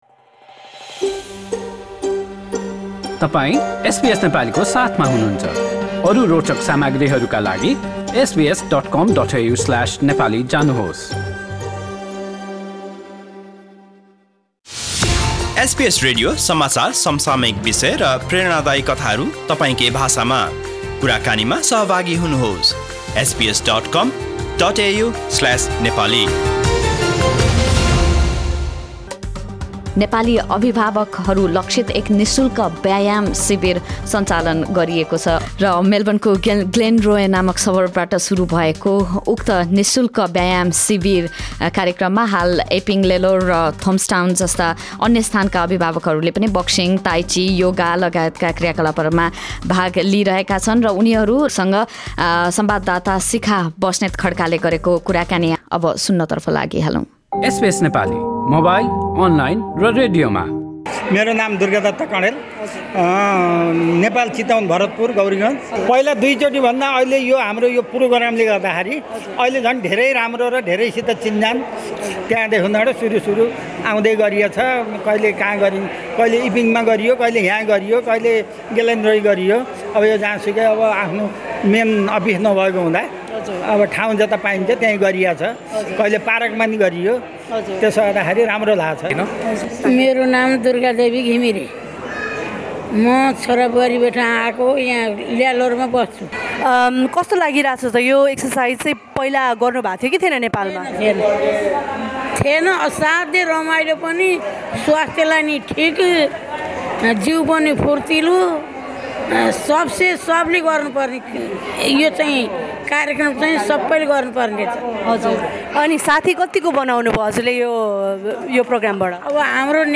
अस्ट्रेलियामा रहेका आफन्तजनहरू भेट्न तथा यहाँ घुमफिर गर्न नेपालदेखि आएका अभिभावकहरूलाई शारीरिक रूपमा तन्दुरुस्त राख्न आयोजित एक व्यायाम शिविरका सहभागीहरूसँग गरिएको कुराकानी